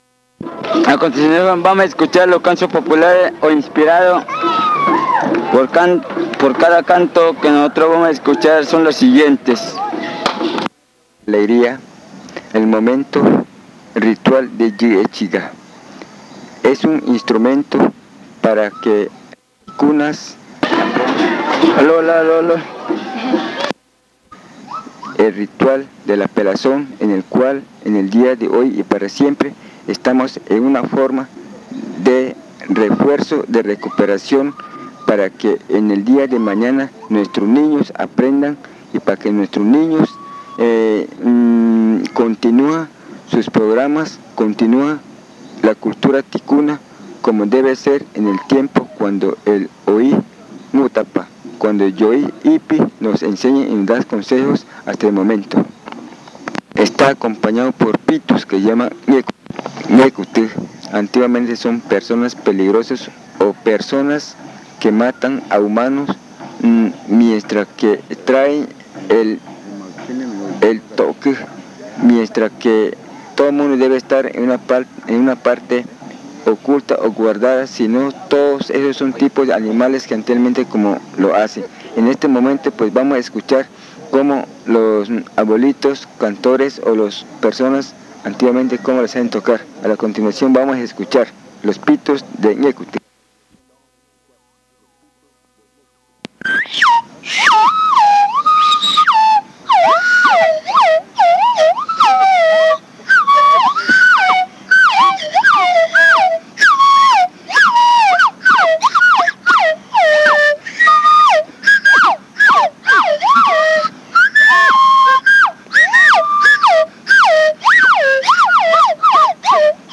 Chants 1. Chants of the magütá puberty ritual
Grabaciones de pitos y aerófonos propios del ritual magütá de la pubertad. El casete solo fue grabado por el lado A y no se especifica fecha. El lugar de recojo fue la Comunidad Indígena de Arara (Amazonas, Colombia).
Recordings of whistles and aerophones typical of the magütá puberty ritual.
The place of collection was the Indigenous Community of Arara (Amazonas, Colombia).